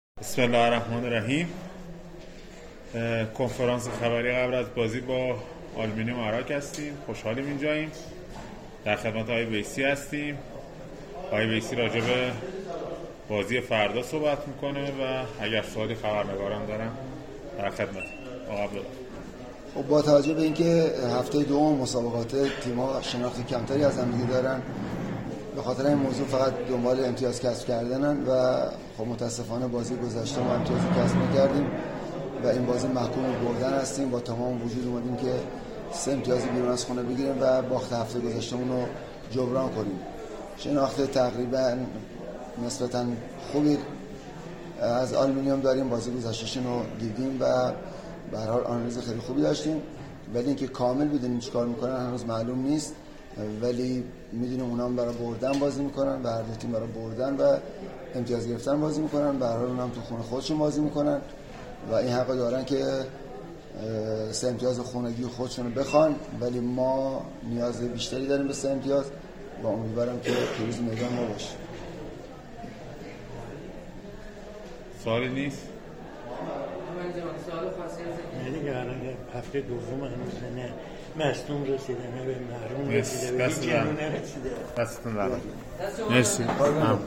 نشست خبری سرمربی فولاد
نشست خبری عبدالله ویسی، سرمربی تیم فولاد پیش از دیدار برابر آلومینیوم اراک برگزار شد.
به گزارش سایت رسمی فدراسیون فوتبال، صحبت‌های عبدالله ویسی، سرمربی تیم فولاد را بشنوید: